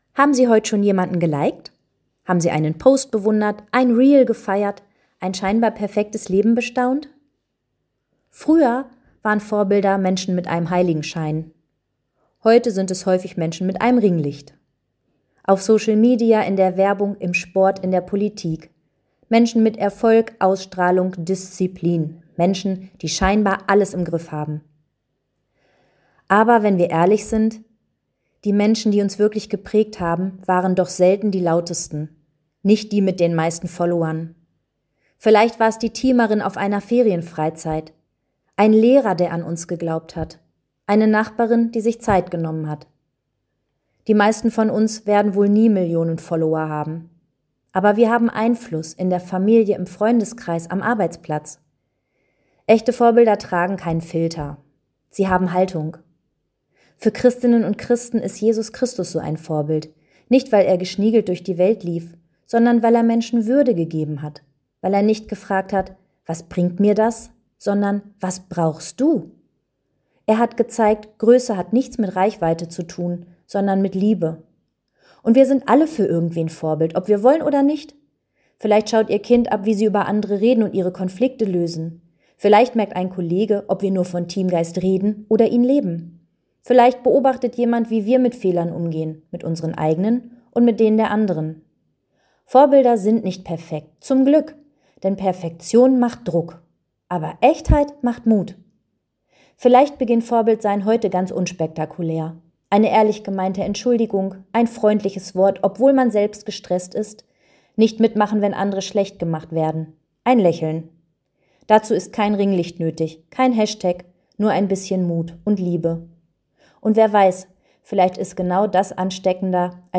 Hameln-Pyrmont: Radioandacht vom 16. März 2026